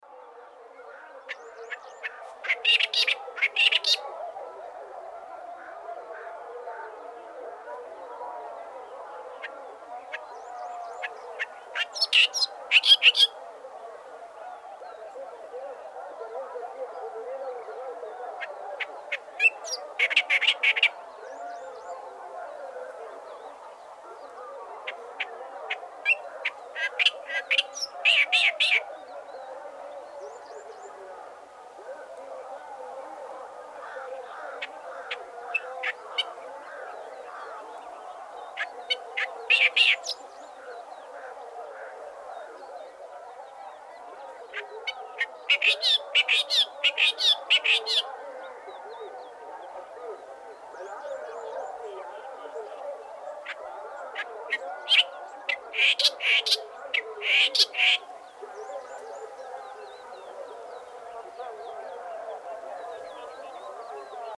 Clamorous Reed Warbler Acrocephalus stentoreus, song
Greater Hoopoe Lark Alaemon alaudipes, song flight